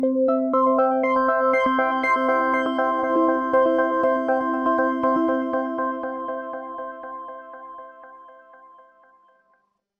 🎵 Background Music
Emotion: hope